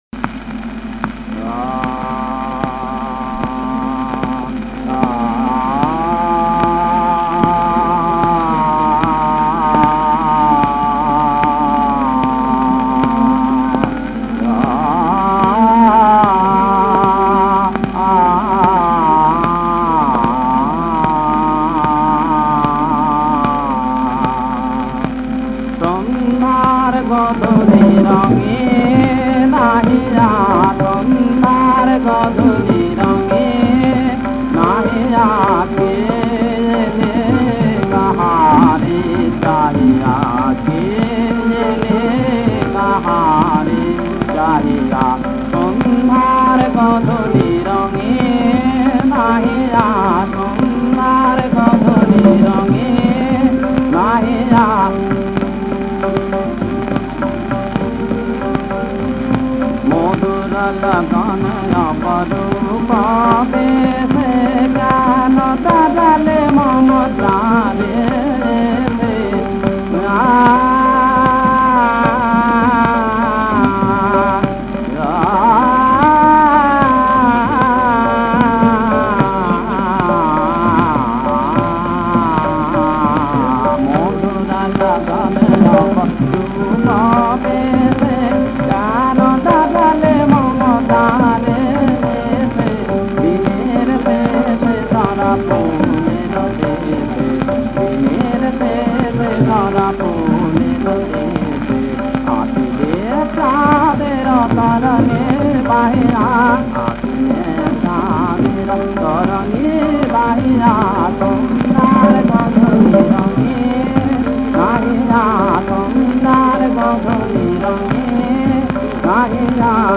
রাগ: ভীমপলশ্রী, তাল: ত্রিতাল
ভীমপলশ্রী-ত্রিতাল।